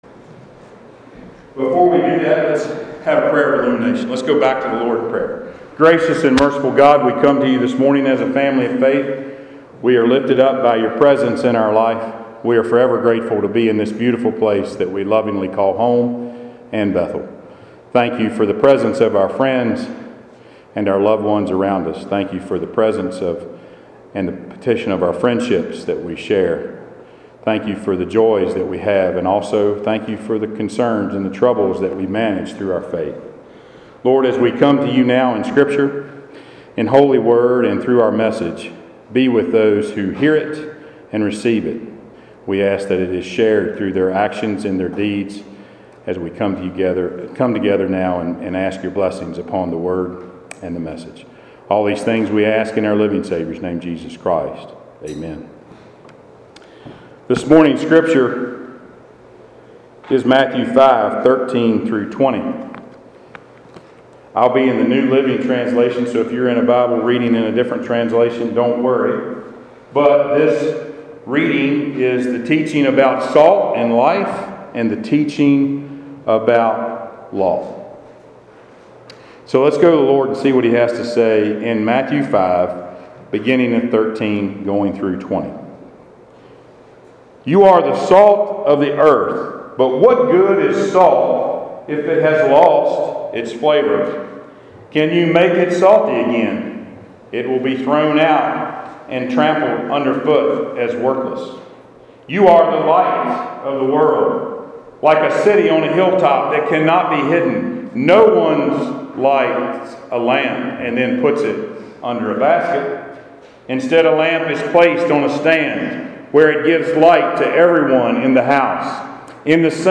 Passage: Matthew 5:13-20 Service Type: Sunday Worship